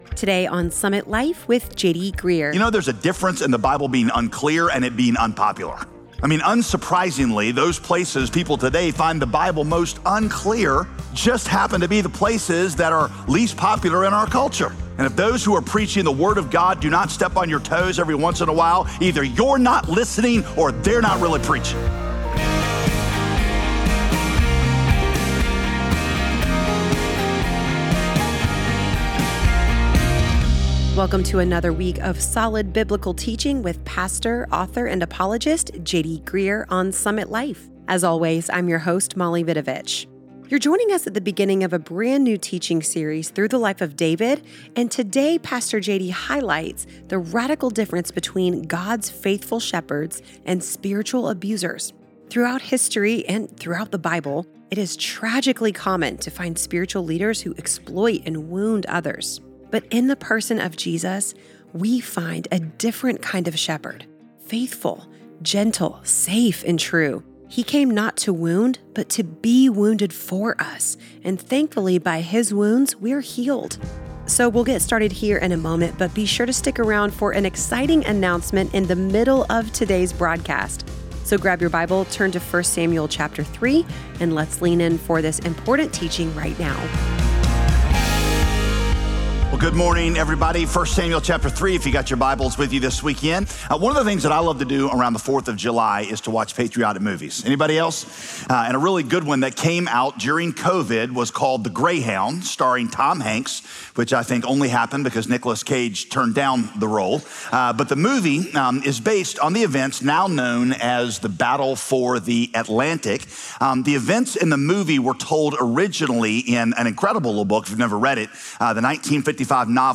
In this teaching, Pastor J.D. highlights the radical difference between God's faithful shepherds and spiritual abusers.